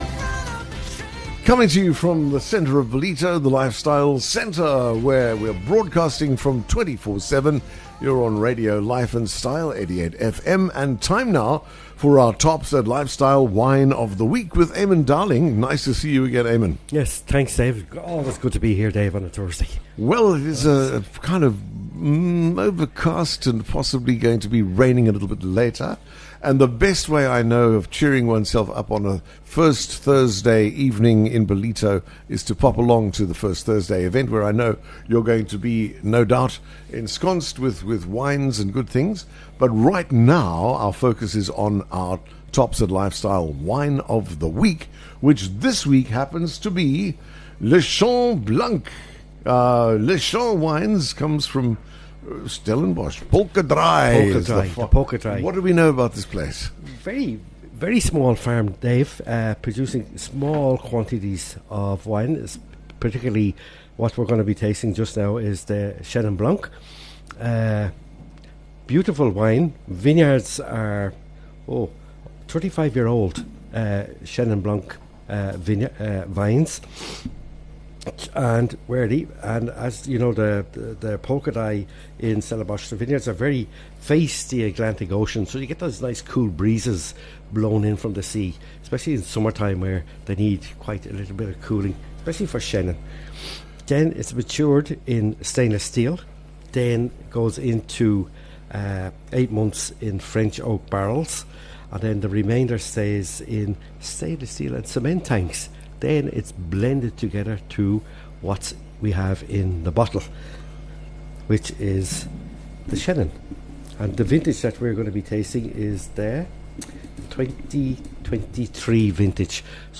Broadcasting live from the heart of Ballito, The Morning Show serves up a curated mix of contemporary music and classic hits from across the decades, alongside interviews with tastemakers & influencers, plus a healthy dose of local news & views from the booming KZN North Coast.